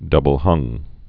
(dŭbəl-hŭng)